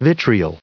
1034_vitriol.ogg